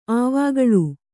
♪ āvāgaḷū